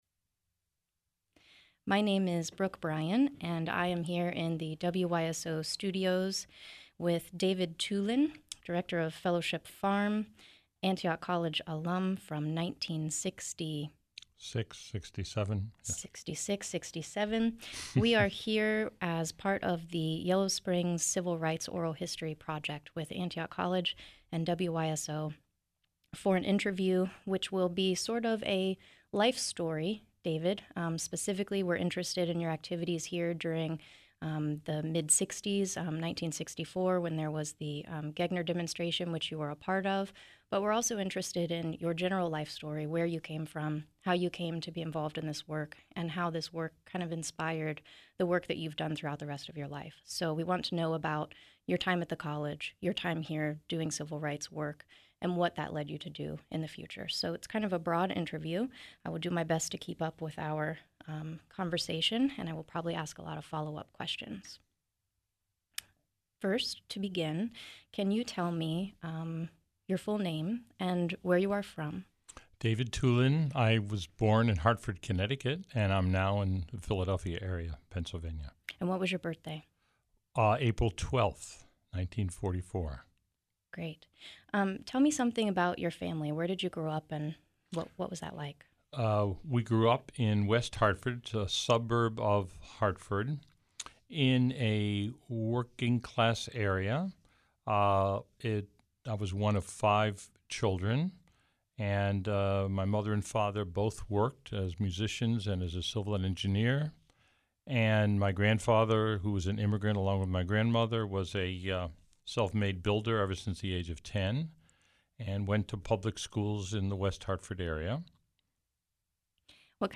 Oral History in the Liberal Arts | Alumni Stories - Antioch's Committee for Racial Equality